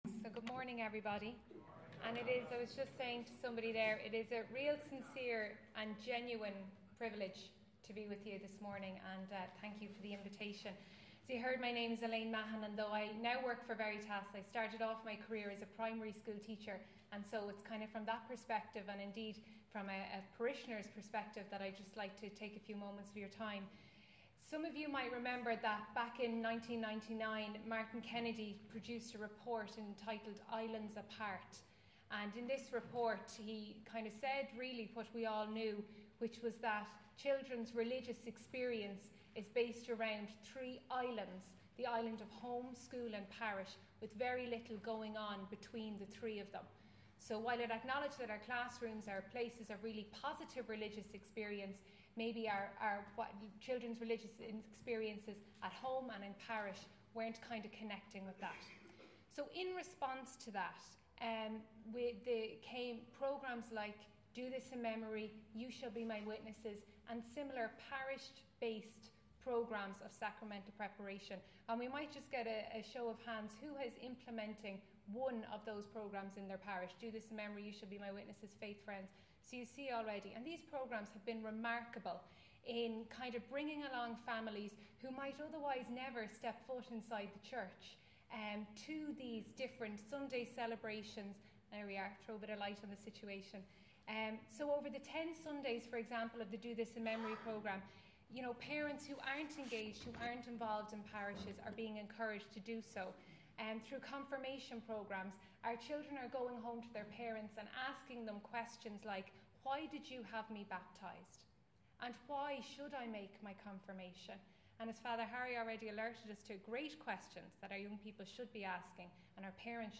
Meath Diocesan Assembly, 24 iv 2012